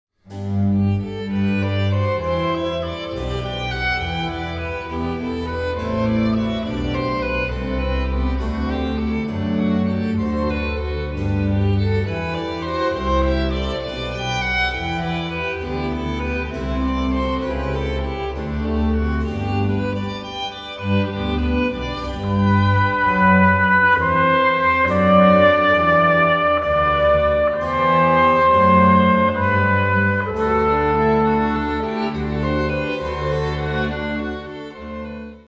vorgetragen mit 2 Trompeten und Kammerorchester
Trompete
Violine
Viola
Violoncello
Contrabass
Cembalo
Oboe
Flöte
* Mitglieder des Tiroler Symphonieorchesters